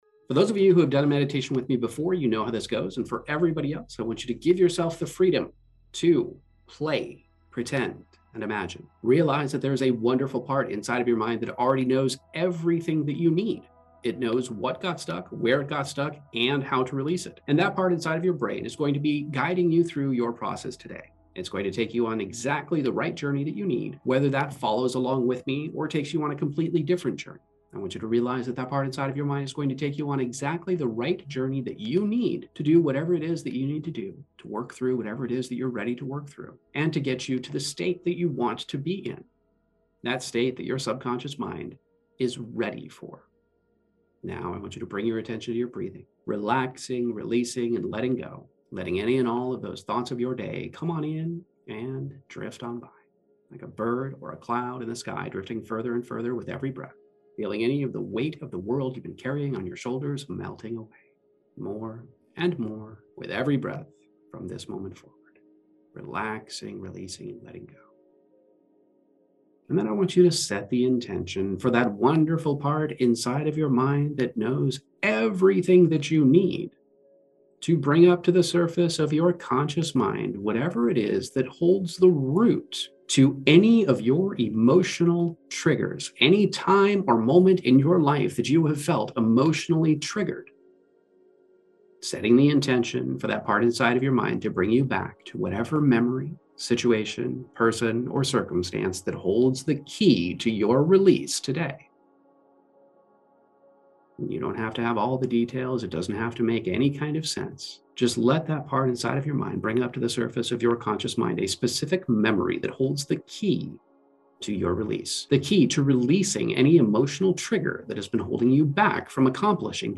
Guided Hypnotherapy Meditation